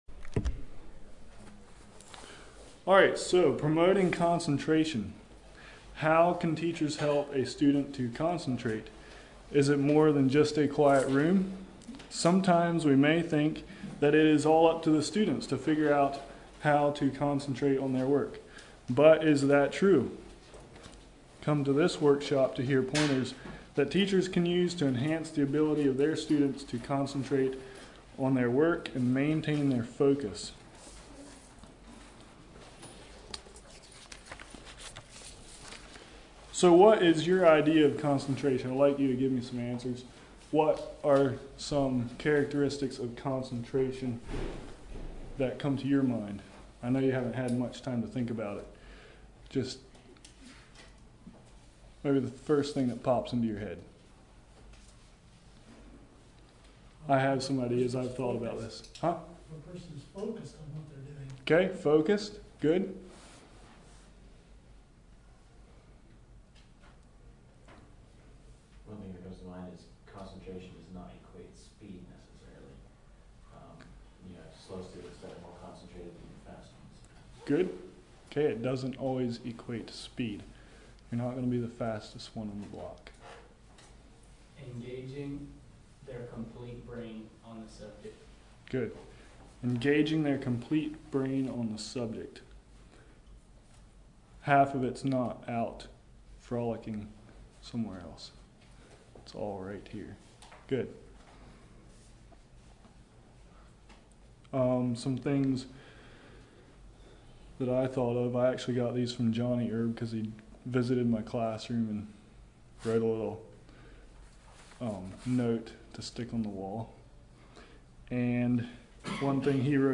Come to this workshop to hear pointers that teachers can use to enhance the ability of their students to concentrate on their work and maintain their focus.